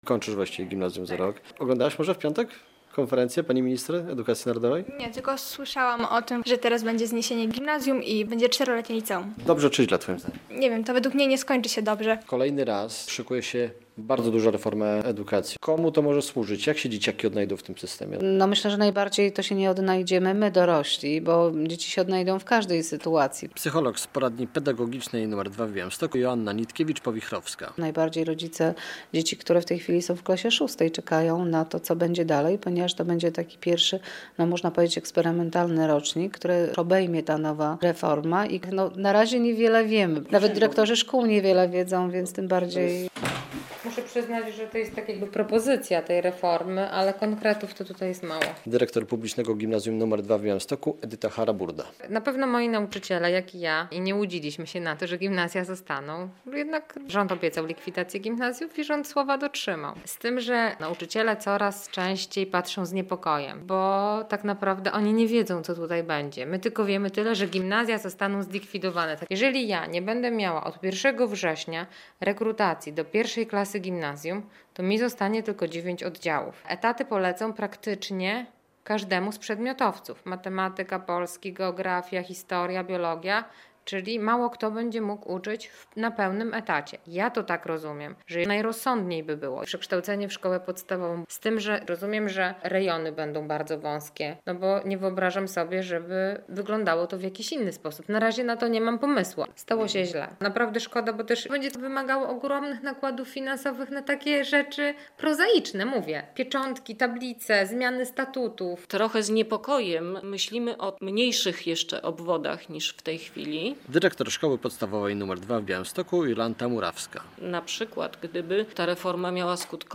Reforma edukacji - co sądzą o niej nauczyciele i uczniowie? - relacja